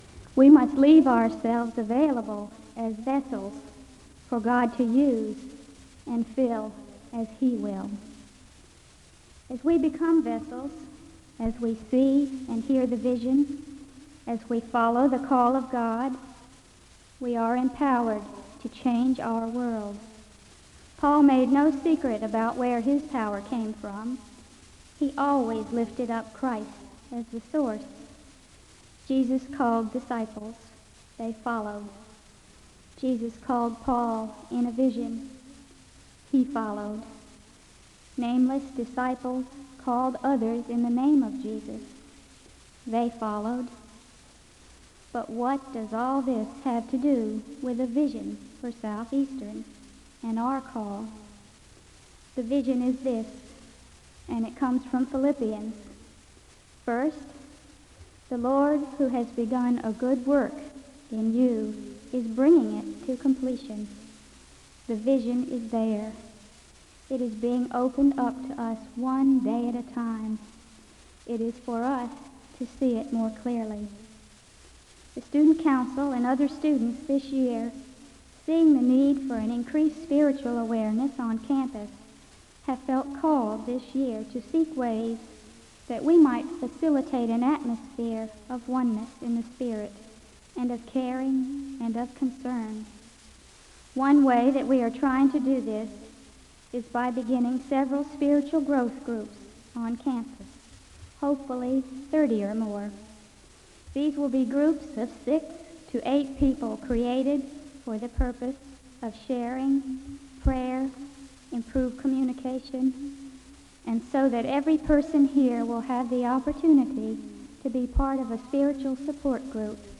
Download .mp3 Description This chapel service is led by the Student Council. The service begins with piano music (0:00-3:46). There is a Scripture reading and a moment of prayer (3:47-5:58). There is a litany of confession (5:59-7:32).
The speaker shares a portion of her testimony (24:26-29:35).